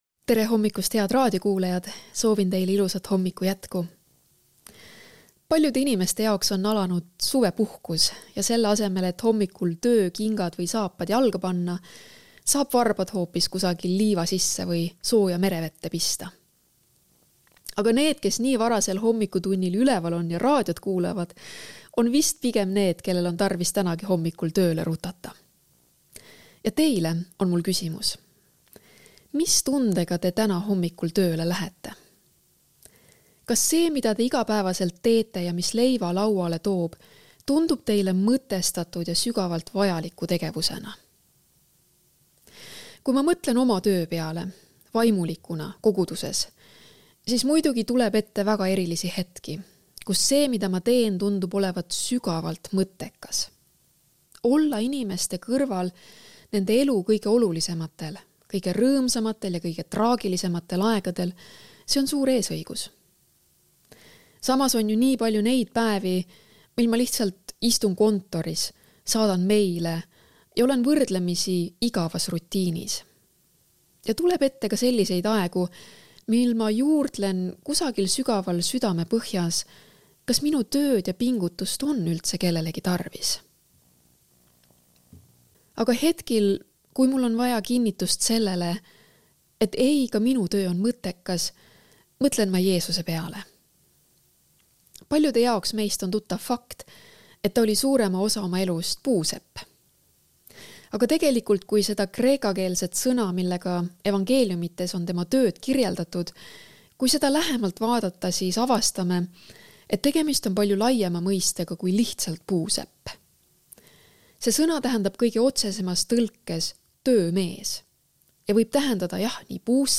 hommikupalvus ERR-is 27.06.2024